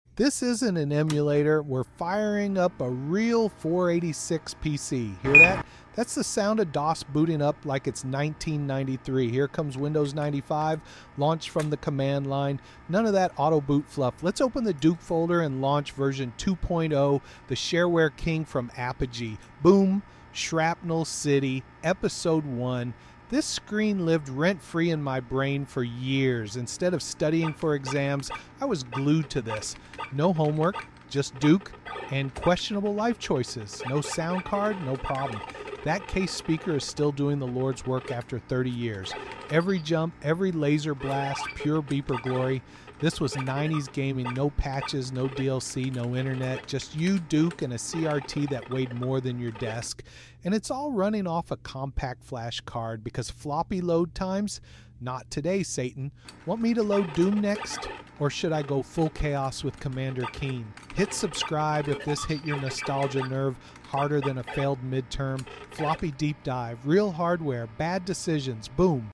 Mp3 Sound Effect This ain’t an emulator — it’s a real 486 PC running Duke Nukem in pure DOS glory. CRT monitor, case speaker, no sound card. Just bloops, bleeps, and badassery.